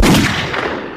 Shot.wav